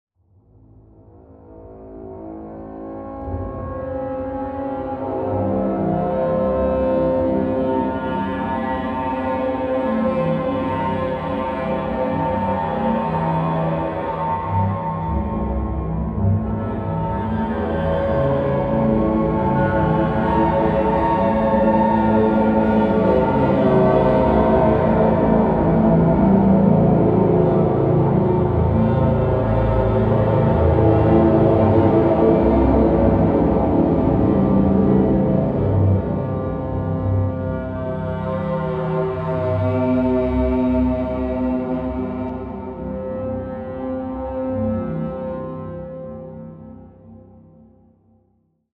Inletaudio Viola Drama Textures 是一款基于小提琴的创意声音设计软件，它可以让您用15种不同的演奏技法来制作富有戏剧性的声音纹理，适合用于即时的场景配乐。
- 15种独特的演奏技法，包括持续音、弓击、即兴演奏、弯音、颤音、颗粒化、泛音等。
- 5种不同的混响和延迟效果，可以为您的声音增加空间感和氛围。
- 2种麦克风位置，可以选择近距离或远距离的声音捕捉。
Inletaudio-Viola-Drama-Textures.mp3